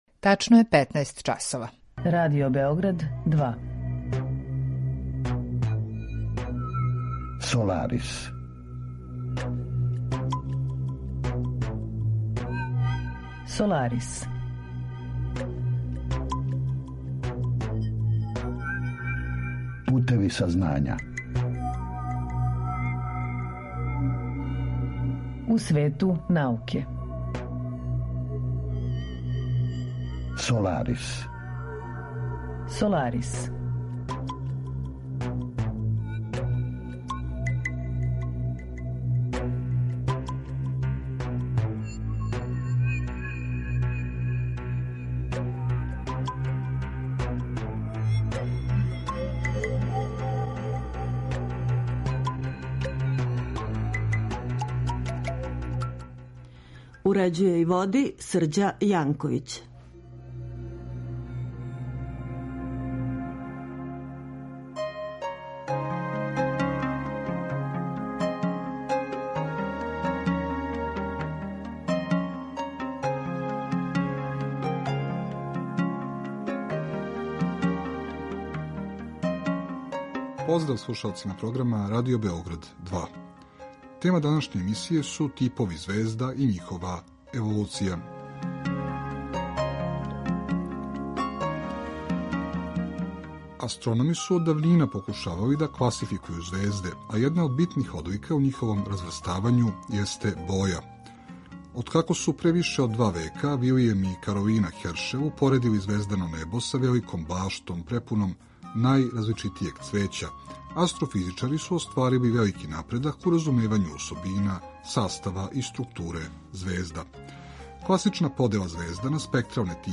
Разговор је први пут емитован 29. августа 2021.